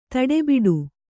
♪ taḍebaḍe